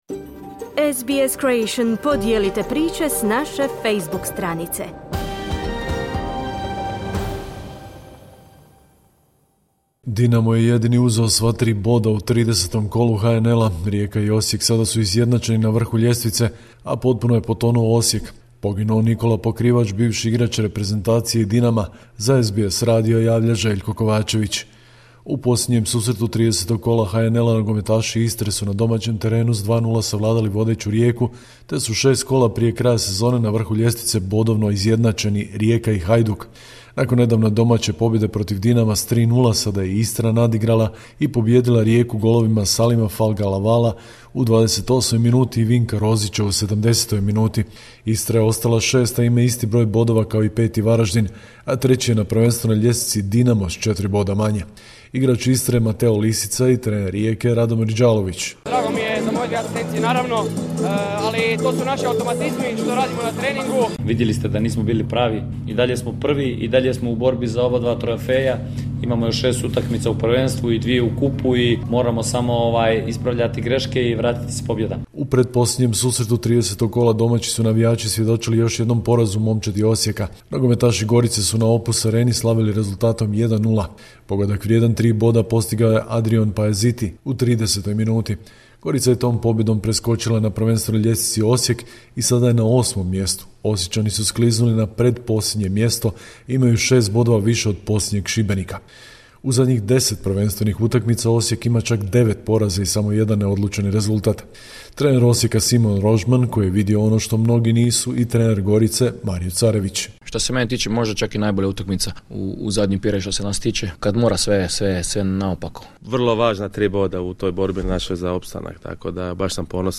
Sportske vijesti, 21.4.2025.